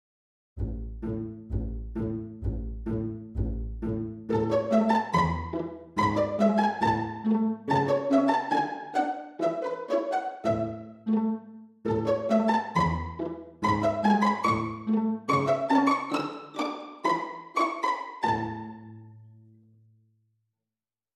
〜ピチカート〜 ピチカートで ちょっとしたイントロつき 00.21